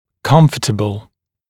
[‘kʌmf(ə)təbl][‘камф(э)тэбл]удобный